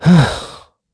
Clause_ice-Vox_Sigh_kr_b.wav